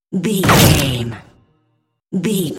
Dramatic shot hit ricochet
Sound Effects
heavy
intense
dark
aggressive